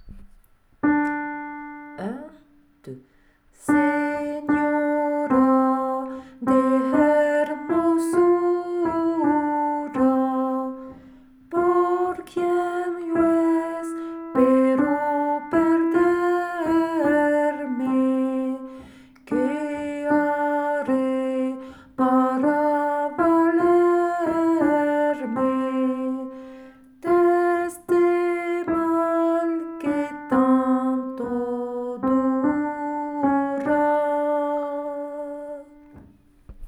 Version à écouter pour la prononciation et la mise en place des syllabes
Alto
senora-de-hermosura-alto.wav